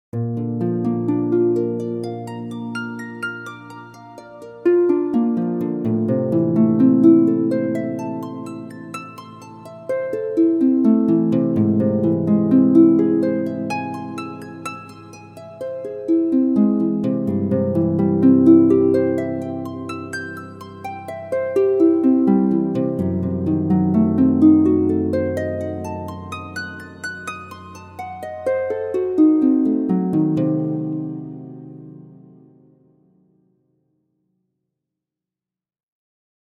Genres: Sound Logo